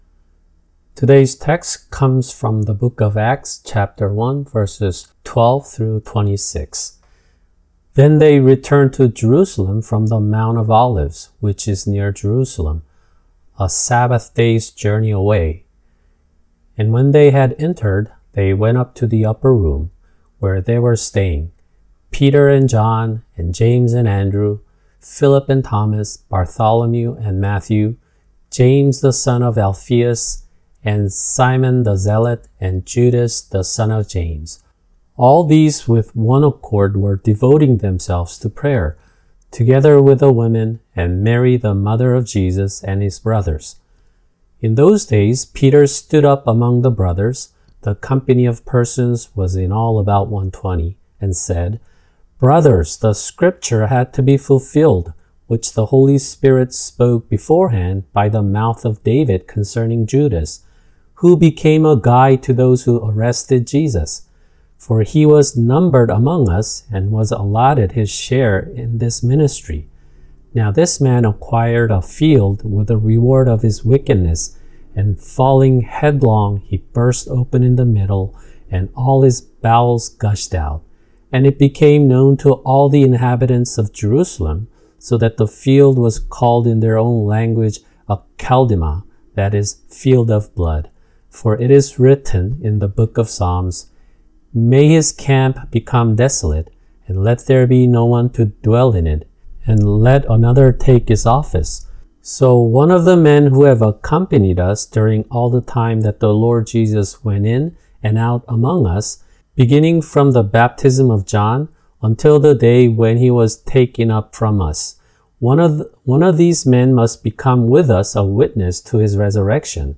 [주일 설교] 사도행전 1:12-26(1)
[English Audio Translation] Acts 1:12-26(1)